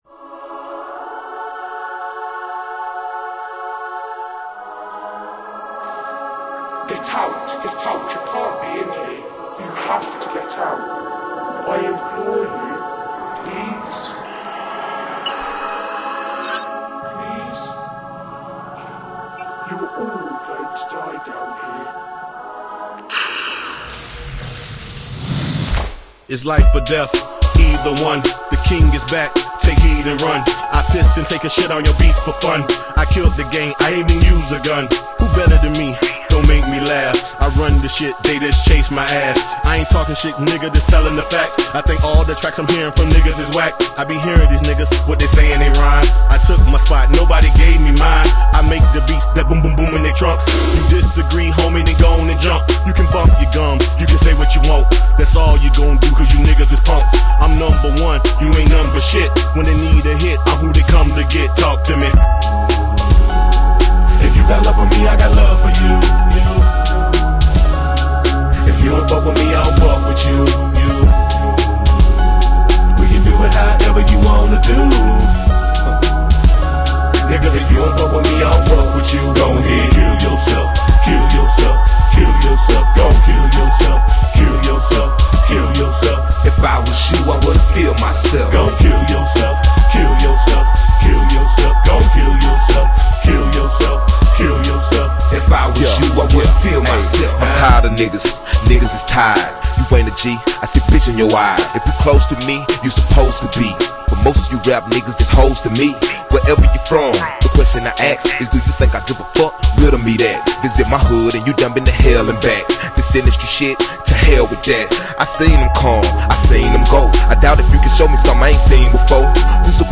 Жанр:Hip-Hop,Rap,R&B,Pop,Rock...